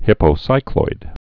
(hīpō-sīkloid)